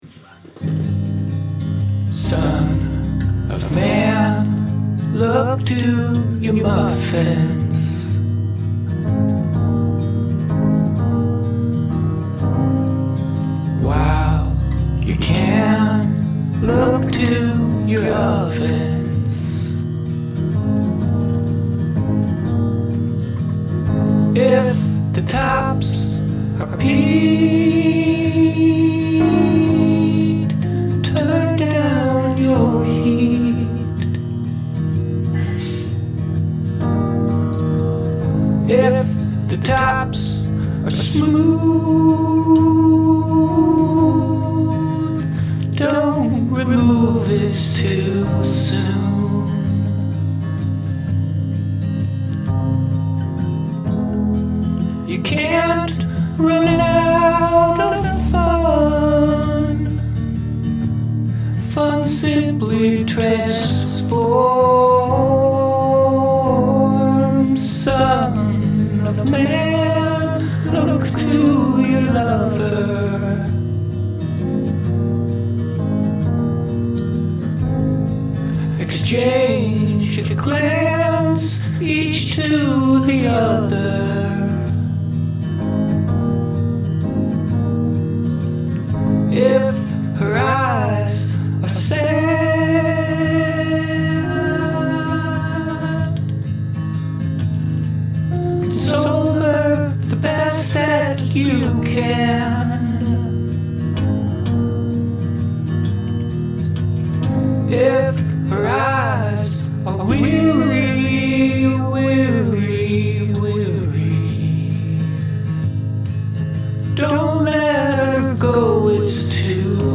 Em, C, G, Em, C, G
G#, C#m, F#, B, Em, C
ABAB
based on this request. doing my best john lennon impersonation...
Someone studying with some chill music in the background
Relaxed